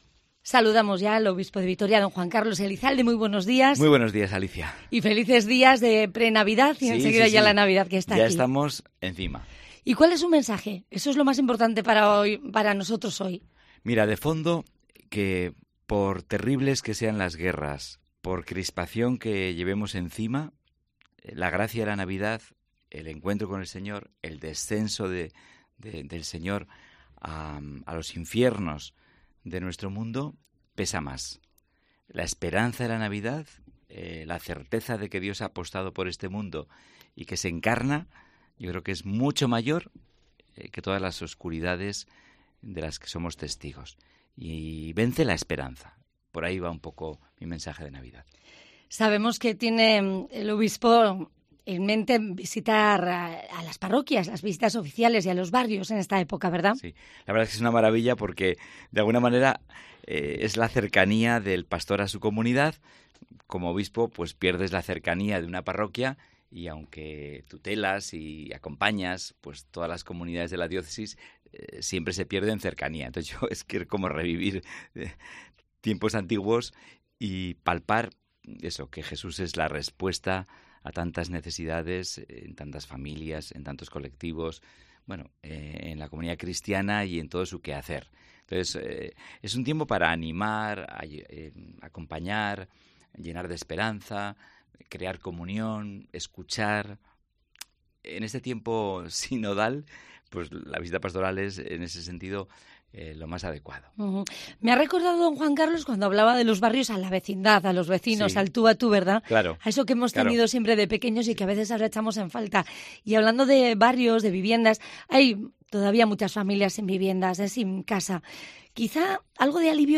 El Obispo de Vitoria lanza en los micrófonos de COPE su mensaje de Navidad y repasa varios temas de actualidad